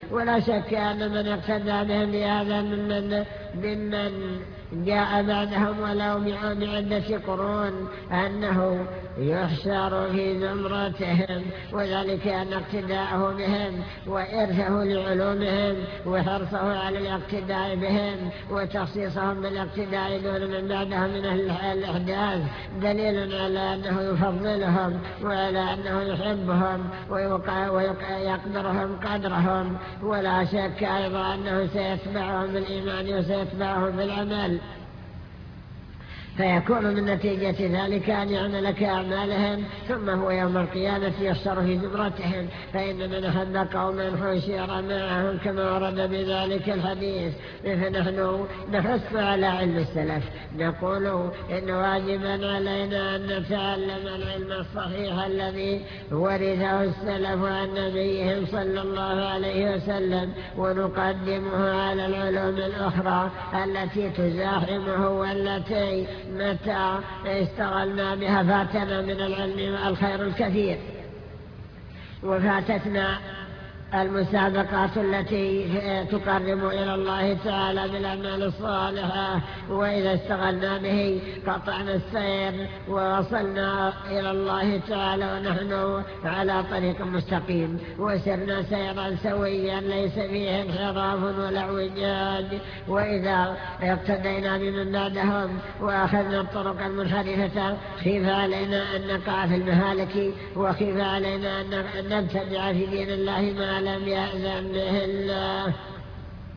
المكتبة الصوتية  تسجيلات - محاضرات ودروس  السلف الصالح بين العلم والإيمان